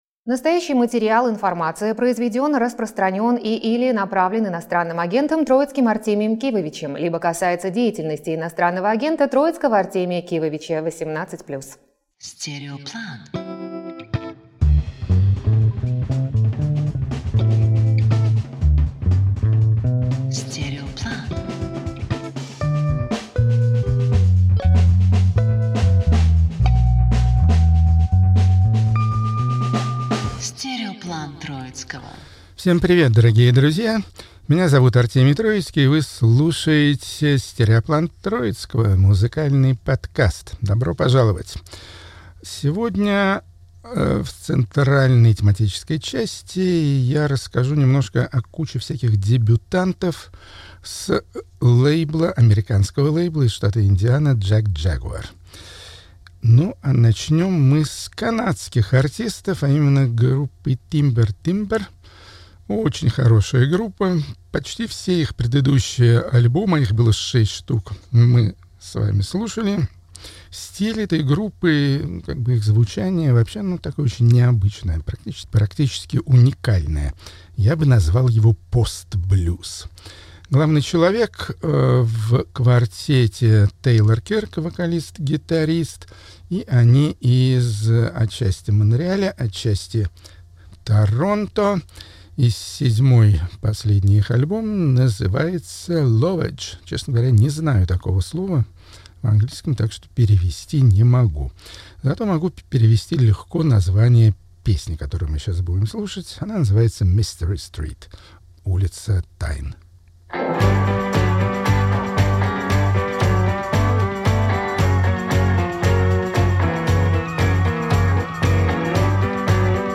Авторская программа Артемия Троицкого